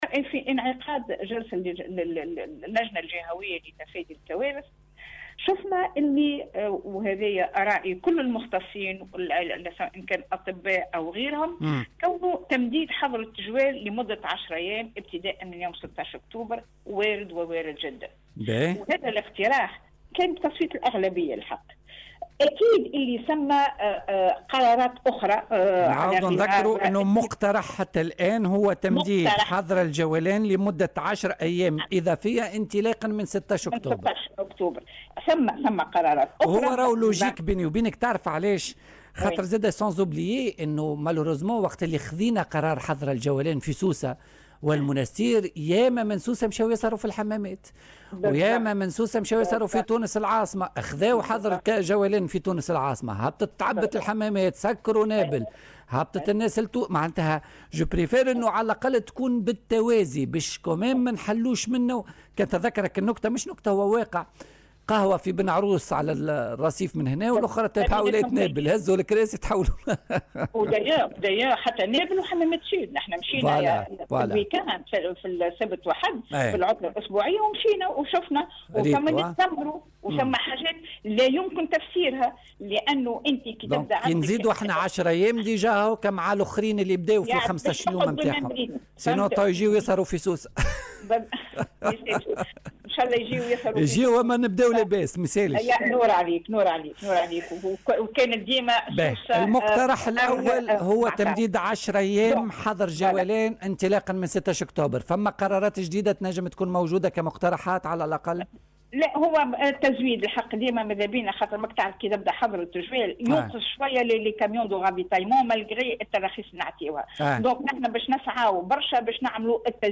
وأضافت في مداخلة لها اليوم على "الجوهرة أف أم" "أن قرار التمديد وارد جدا"، مشيرة في السياق نفسه إلى أنه سيتم العمل على ضمان استمرارية العمل بالمؤسسات التربوية والتكوينية وتزويد السوق بجميع المواد والمنتوجات الحساسة والأساسية.